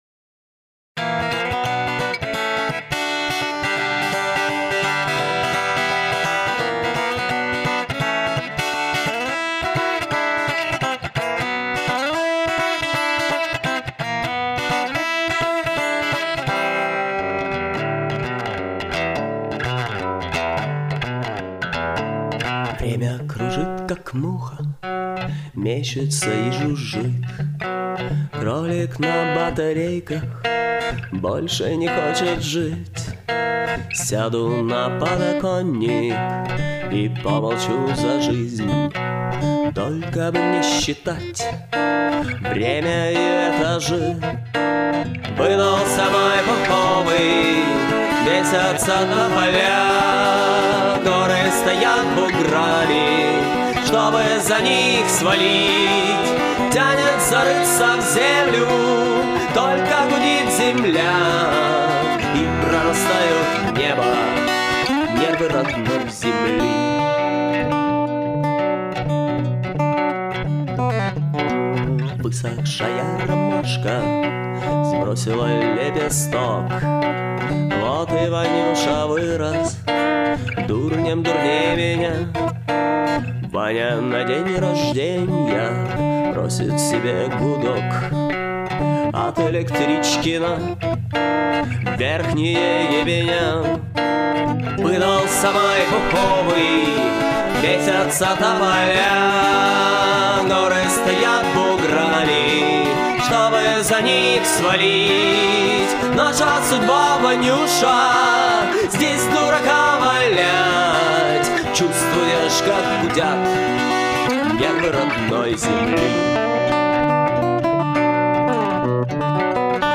Вот еще одна свежезаписанная демка новой песни.